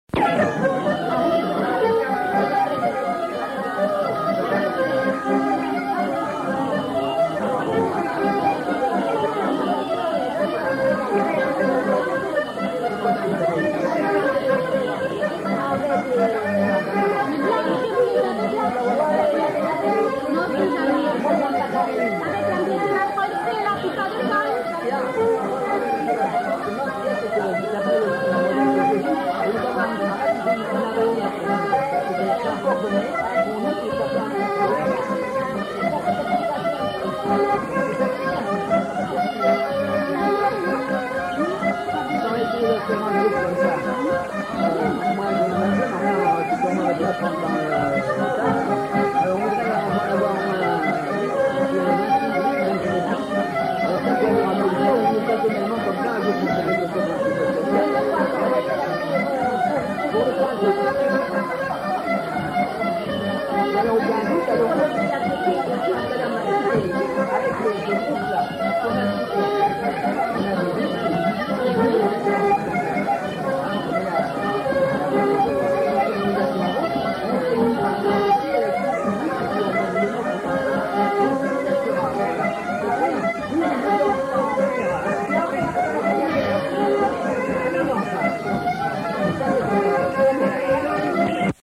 Aire culturelle : Haut-Agenais
Lieu : Cancon
Genre : morceau instrumental
Instrument de musique : accordéon diatonique ; violon
Danse : bourrée
Notes consultables : Le joueur d'accordéon n'est pas identifié.